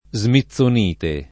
smithsonite [ @ mit S on & te ]